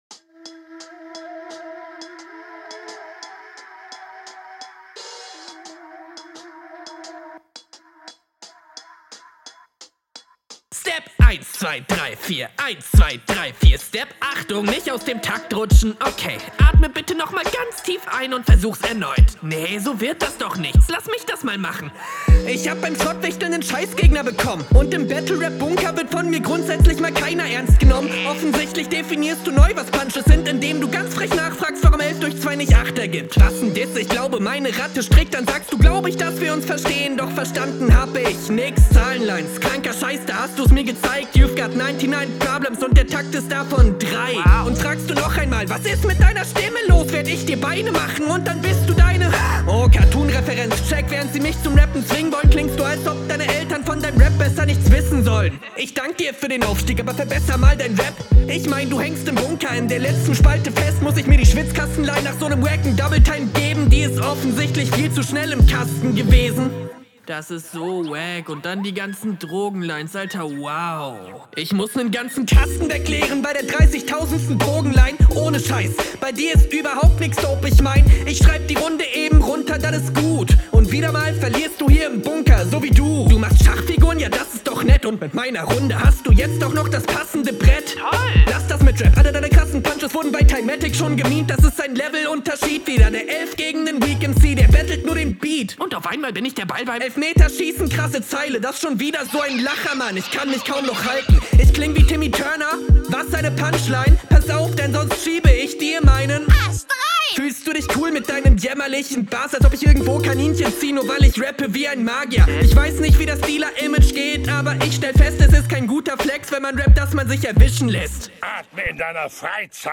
Ziemlich offensichtlich wieder ein ganzes Stück sicherer gerappt.
Guter Flow in der Runde, zu gut für Bronze.